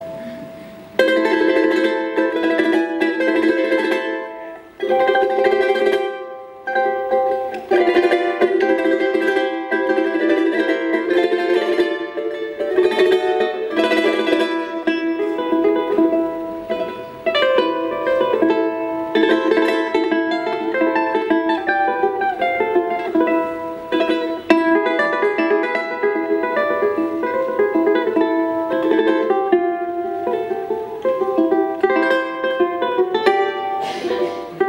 TIMPLE Típico de las Islas Canarias, es un pequeño instrumento de cinco cuerdas, de origen bereber, que se usa para acompañar canciones o como instrumento solista.